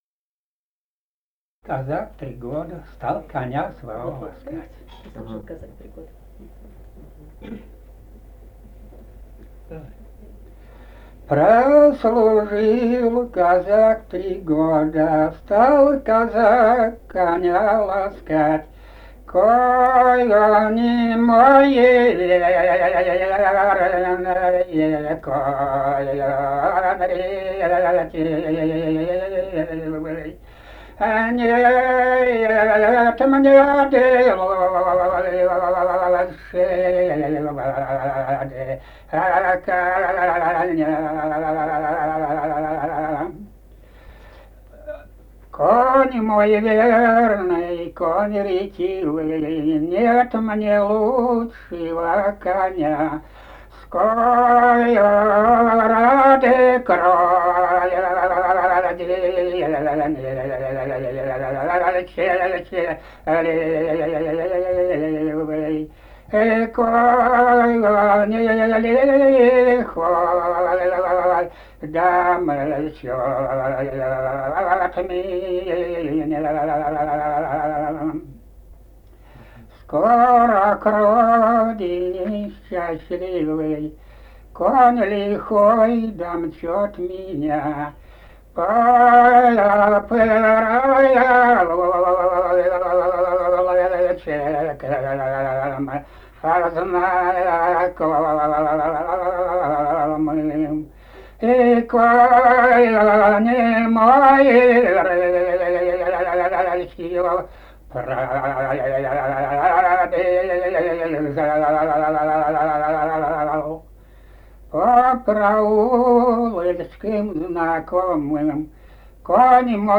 полевые материалы
«Прослужил казак три года» (лирическая).
Казахстан, г. Уральск, 1972 г. И1312-03